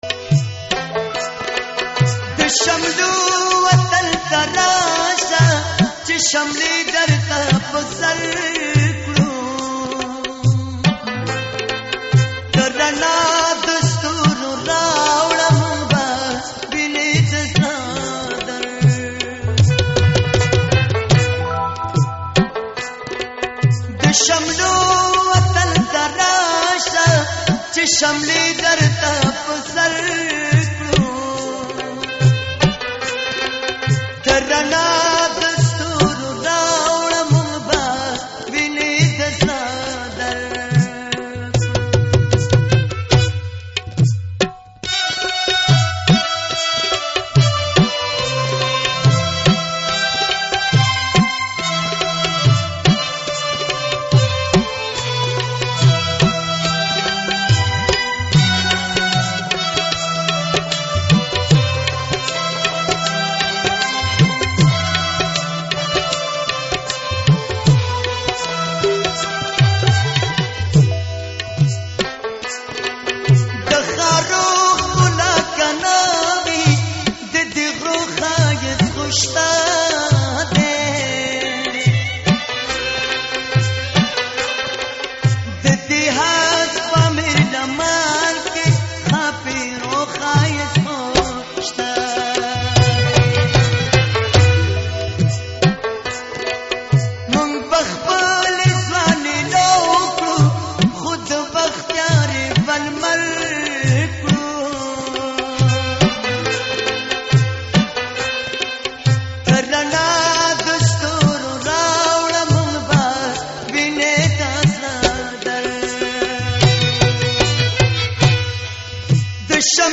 ځوان سندرغاړي